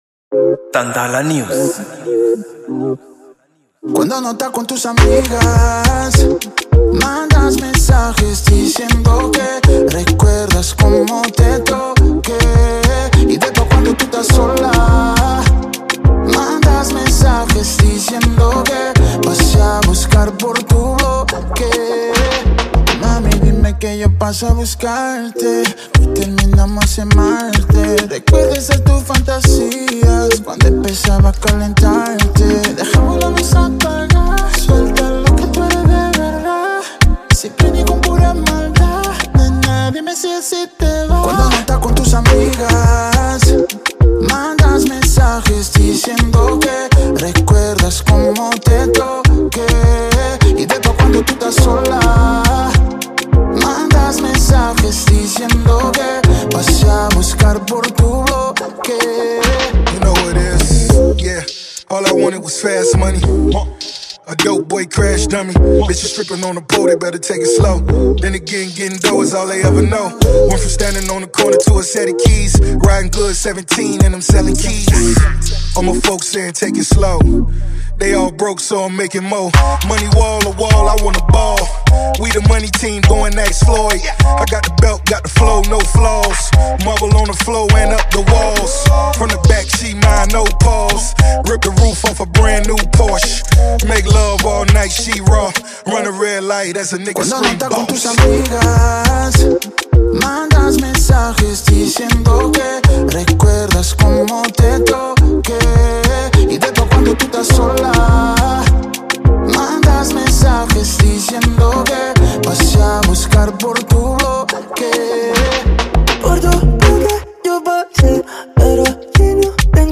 Genero: Reggaeton